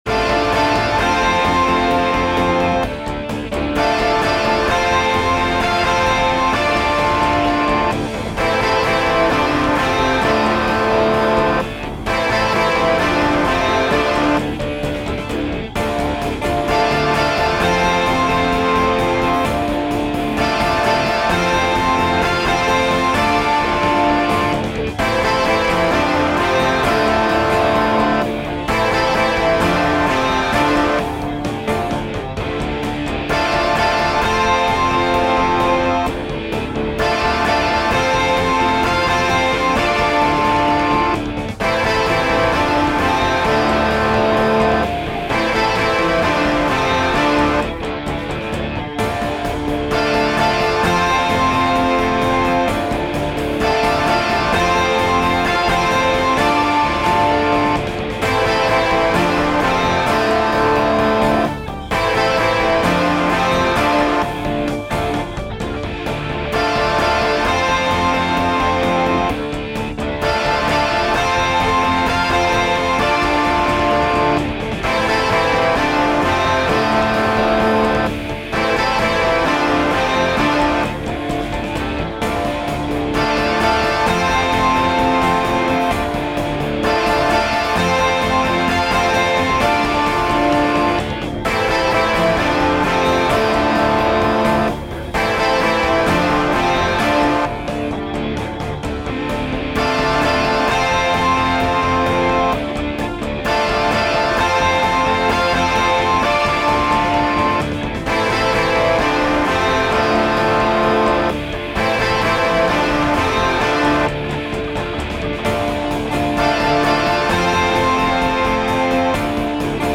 midi-demo 3